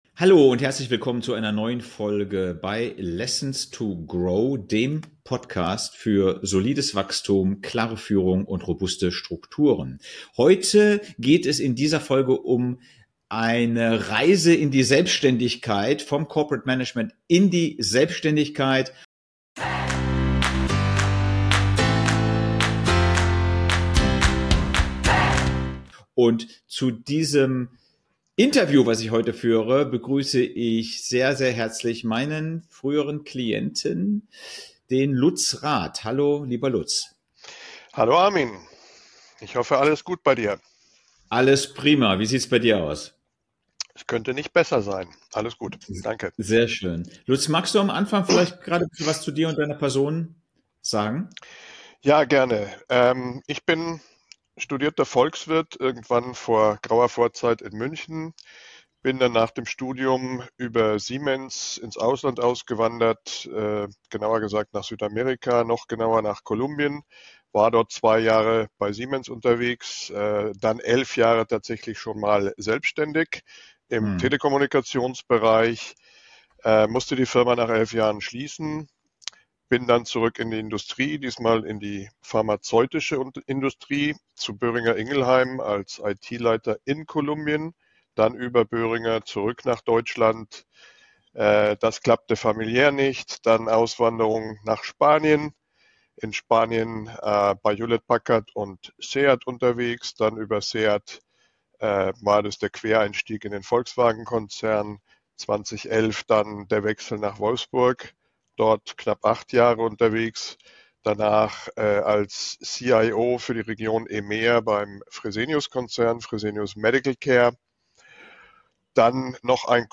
Nr. 44 - Endlich die unternehmerische Freiheit mit Selbständigkeit erreicht. Interview